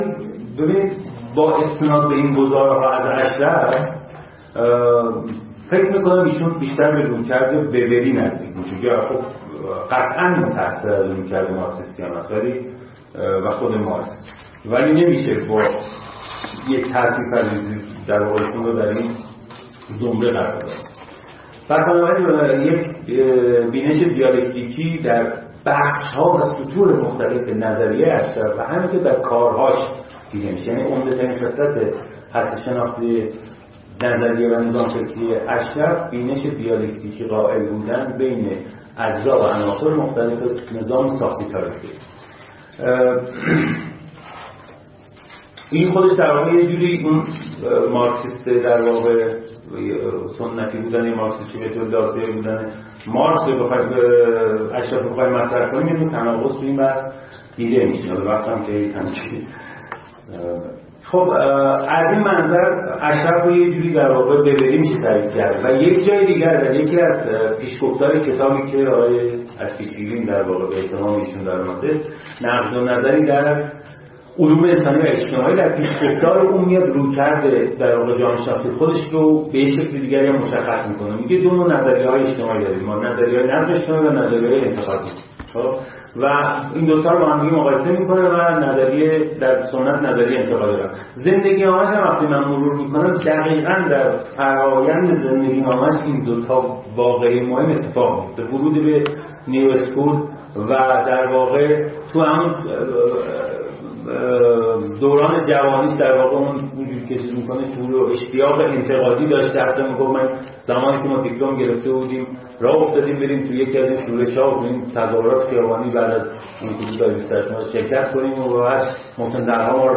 فرهنگ امروز: نخستین نشست از سلسله نشست‌های تاریخ نظریه ای/مفهومی و جامعه شناسی تاریخی ایران با موضع بررسی انتقادی کارنامه علمی احمد اشرف به همت فصلنامه مردم نامه و با همکاری و همراهی انجمن ایرانی تاریخ، انجمن جامعه شناسی ایران، خانه اندیشمندان علوم انسانی، انجمن علوم سیاسی ایران، دانشکده علوم اجتماعی دانشگاه تهران وموسسه نگارستان اندیشه در روز ۱۵ اردیبهشت ماه۱۳۹۷در سالن علی شریعتی دانشکده علوم اجتماعی دانشگاه تهران برگزار شد.